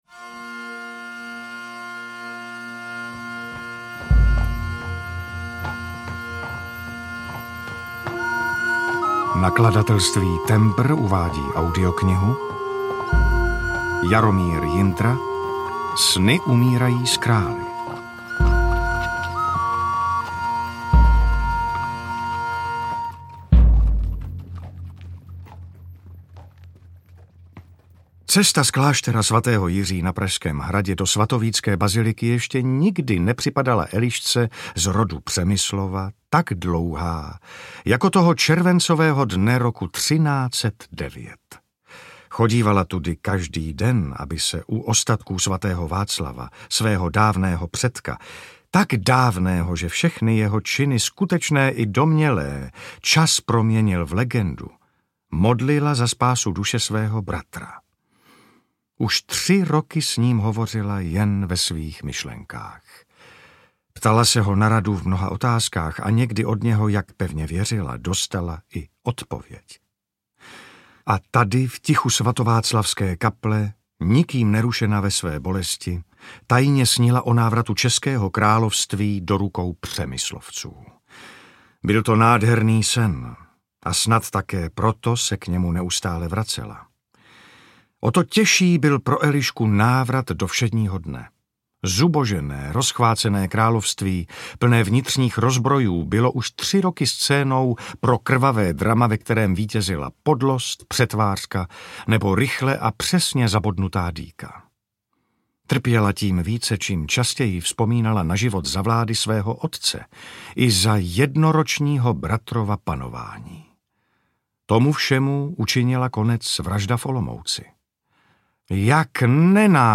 Sny umírají s králi audiokniha
Ukázka z knihy